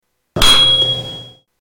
Tuning fork 10
Tags: Tuning Fork Tuning Fork sounds Tuning Fork clips Tuning Fork sound Sound effect